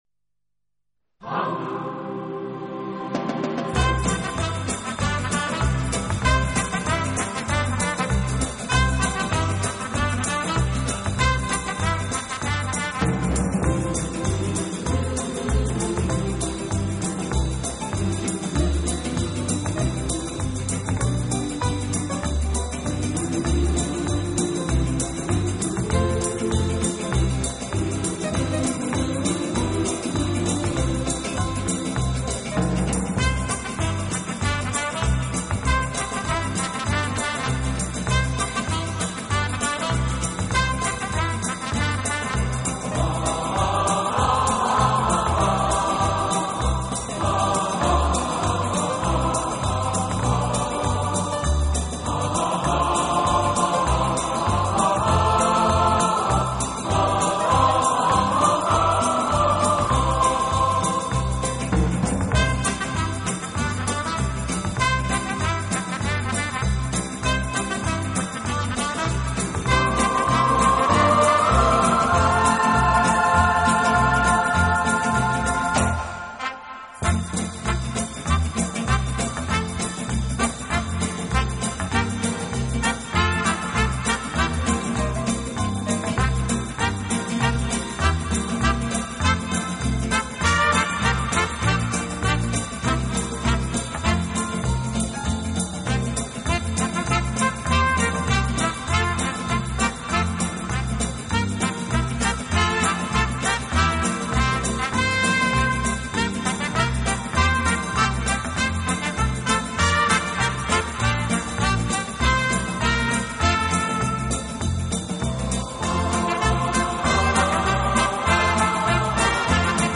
【顶级轻音乐】
录制方式：AAD
这张专辑运用了一些不常用的器乐，增加了神秘感和趣味感。